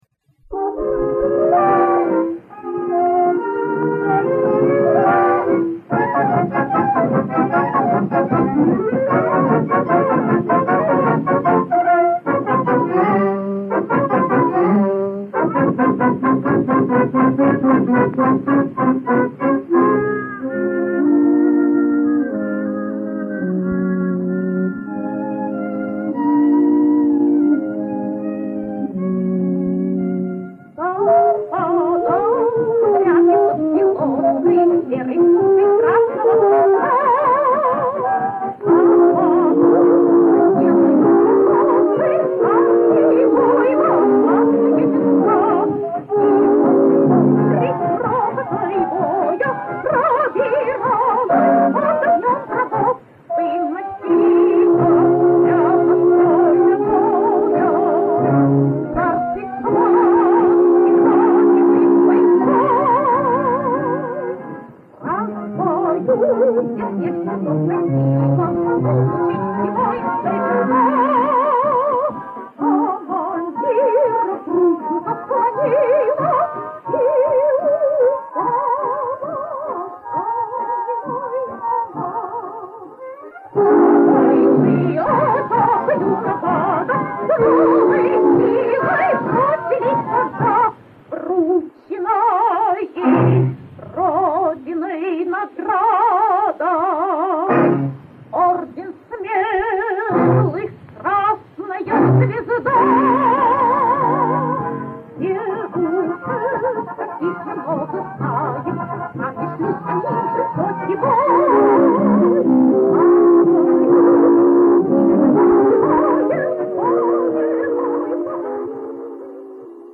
Песня времён ВОВ
Источник аудиокассета